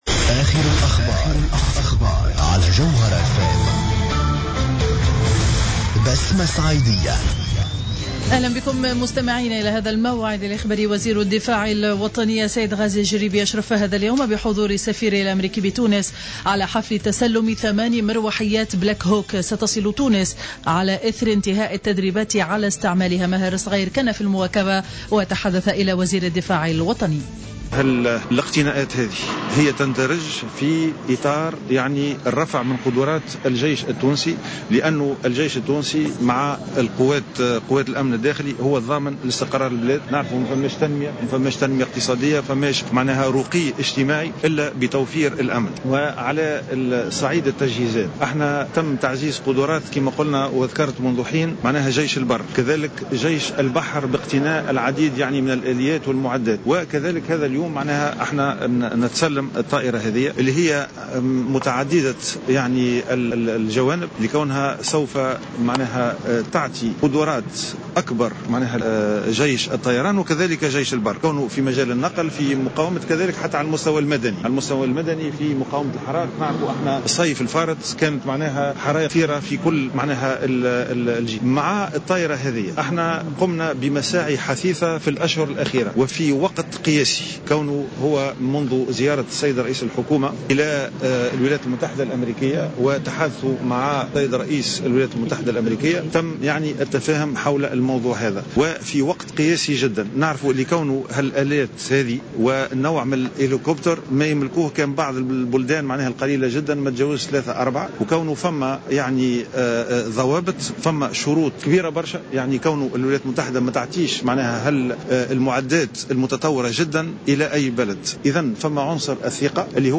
نشرة أخبار منتصف النهار ليوم الخميس 08-01-15